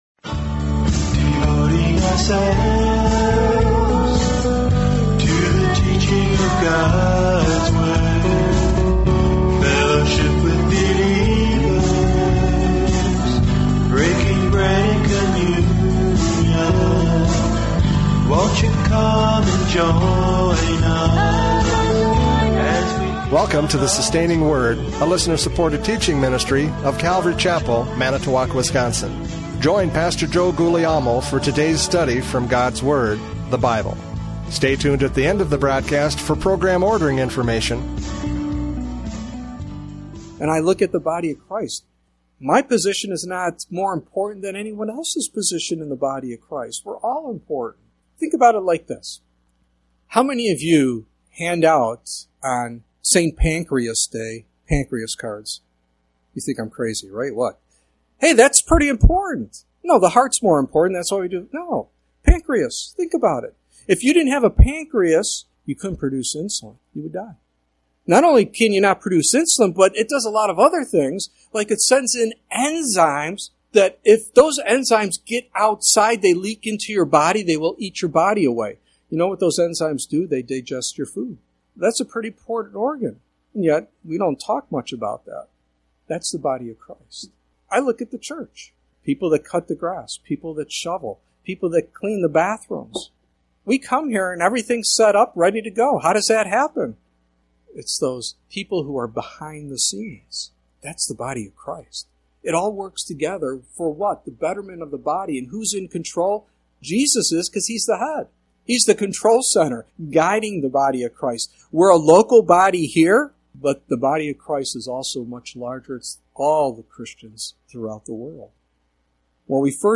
Ephesians 4:1-16 Service Type: Radio Programs « Ephesians 4:1-16 Christmas 2020 The Coming King!